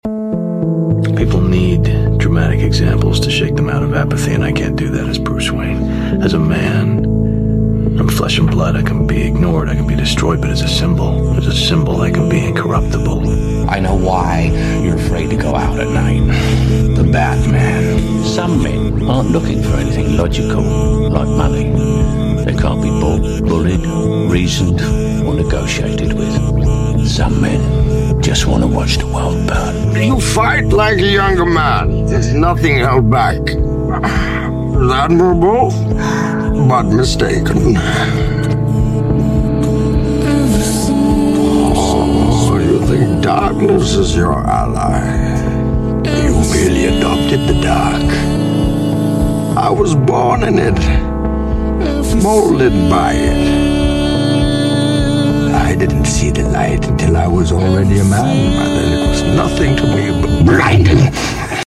BMW M1 Procar 1979, Le Mans Classic 2025, Grid 6